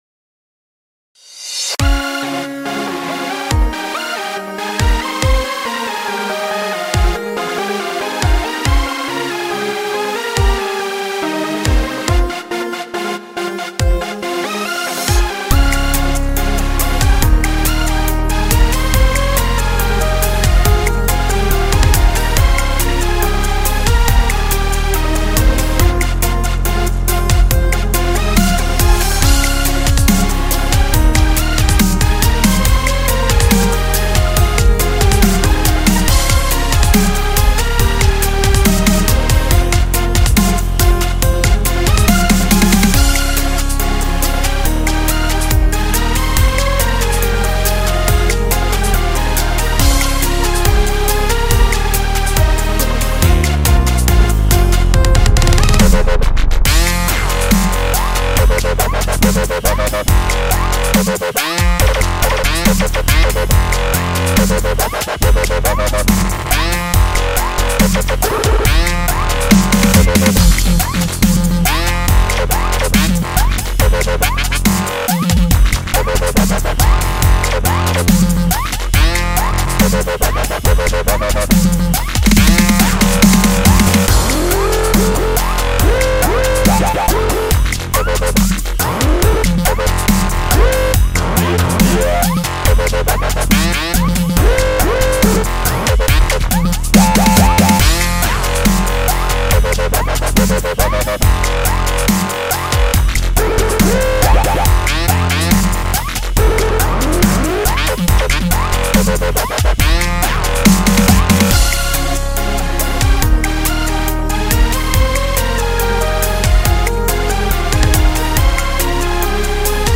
P.S. I SOLD OUT YOU GUYS AND DID DUBSTEP SOBSOB
electronic music